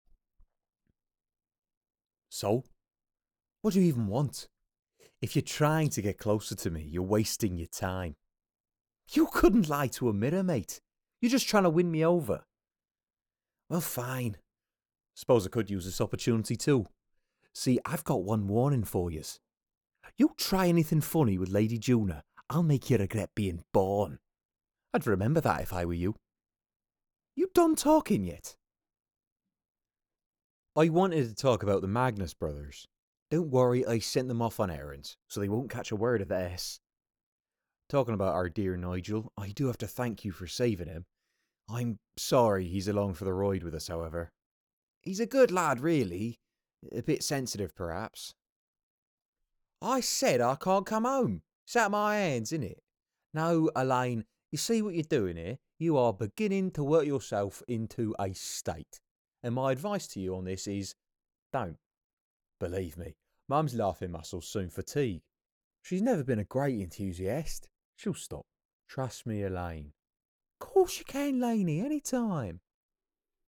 Neutral English, Warm, Engaging, Playful, Comedic